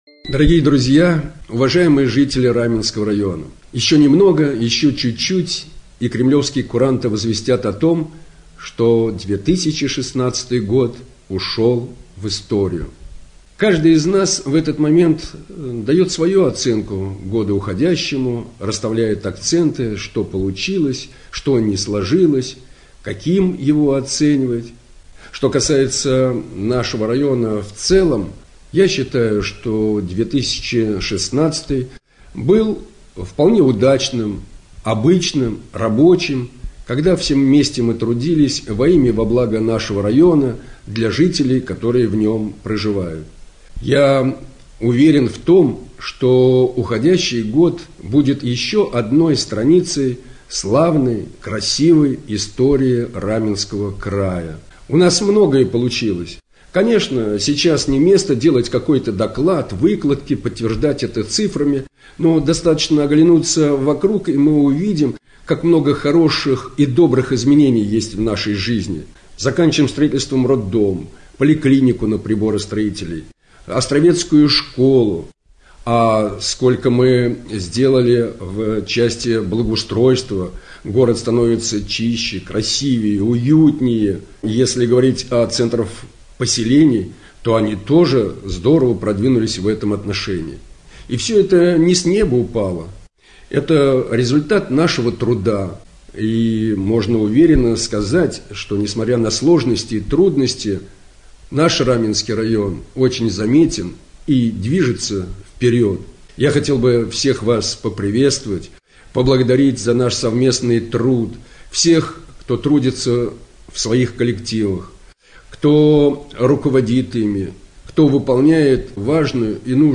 3.С наступающим новым годом поздравляет Глава городского поселения Раменское В.Ф.Демин.